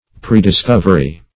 Prediscovery \Pre`dis*cov"er*y\, n. A previous discovery.